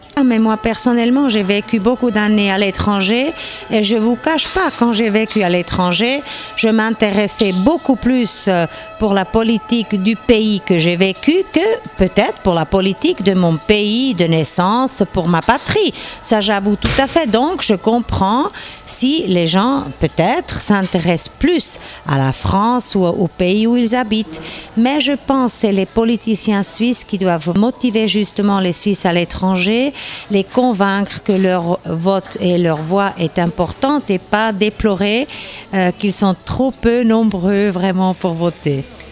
Doris Fiala, députée libérale-radicale au Parlement fédéral